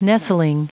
Prononciation du mot nestling en anglais (fichier audio)
nestling.wav